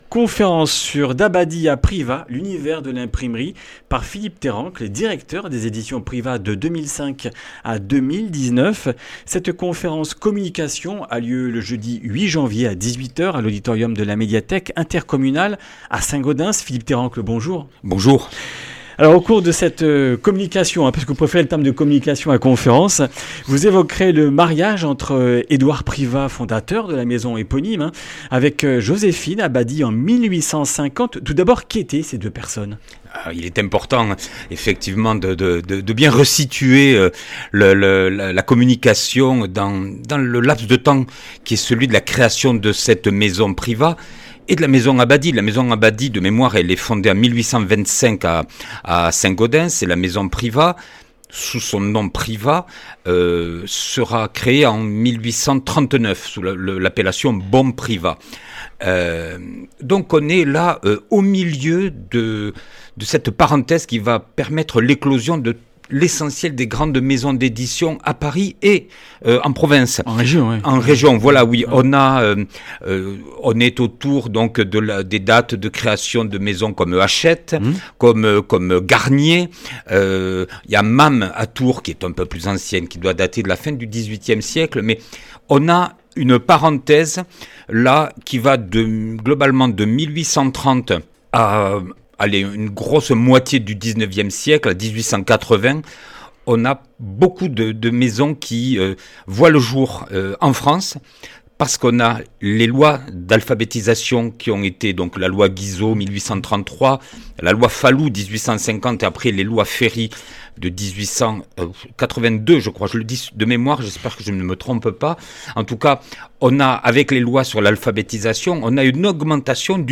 Comminges Interviews du 06 janv.